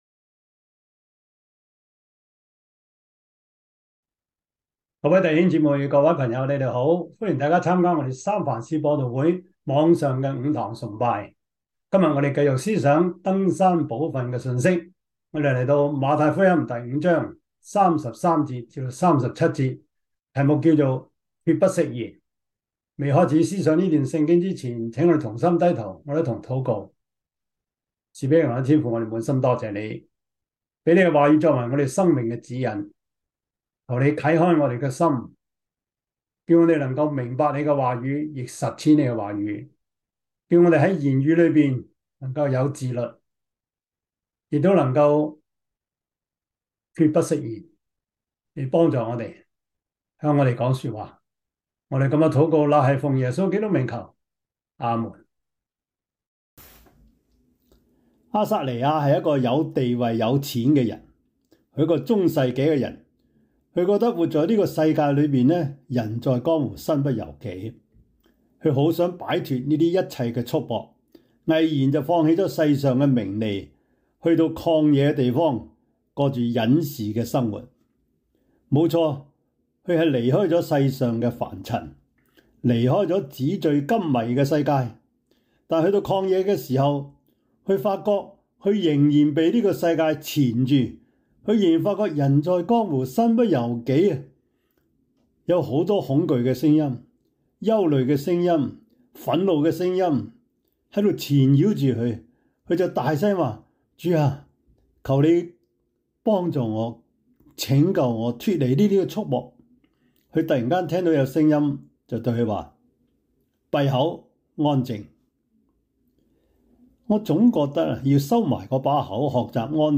馬太福音 5:33-37 Service Type: 主日崇拜 馬太福音 5:33-37 Chinese Union Version